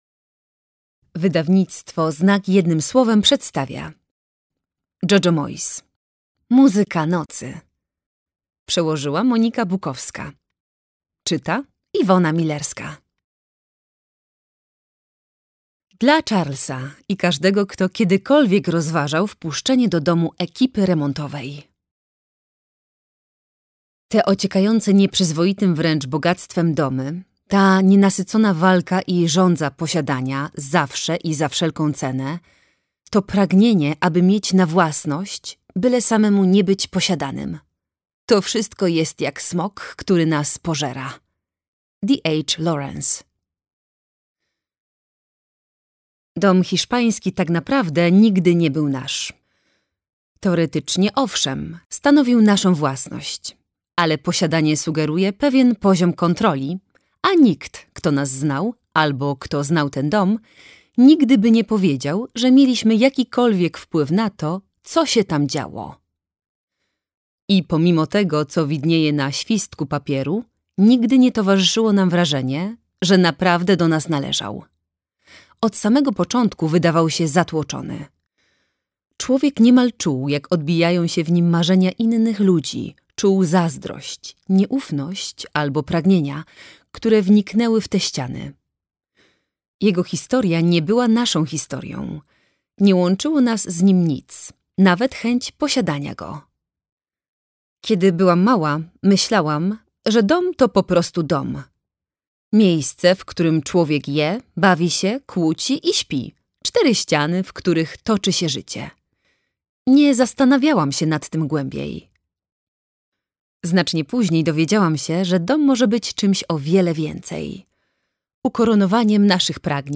Muzyka nocy - Moyes, Jojo - audiobook